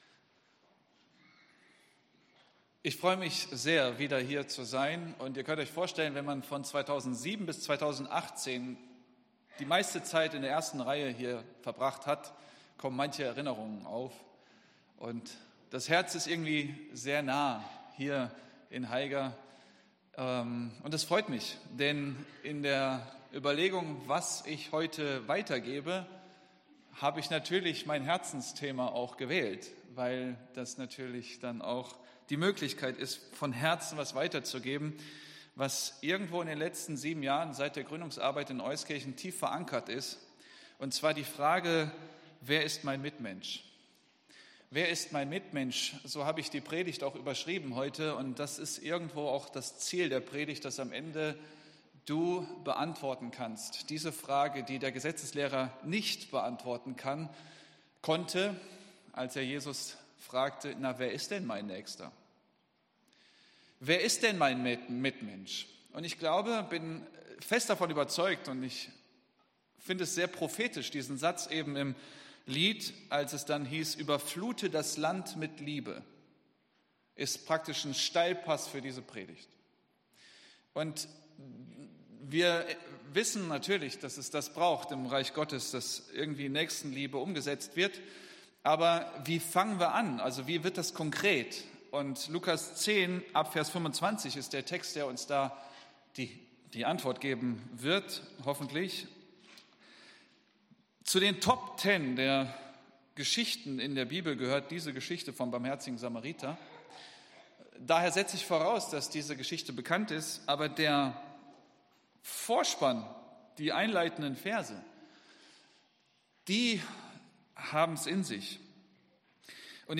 Wer ist mein Mitmensch? ~ EFG-Haiger Predigt-Podcast Podcast